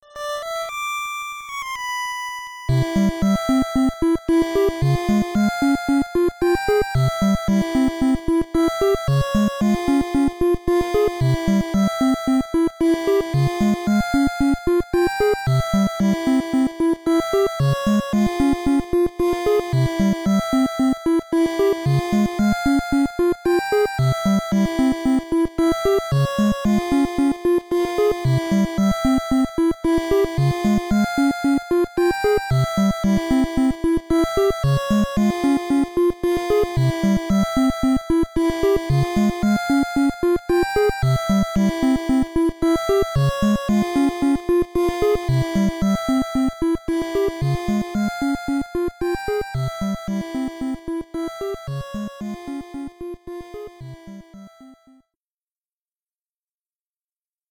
whistle